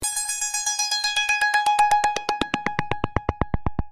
Wecktöne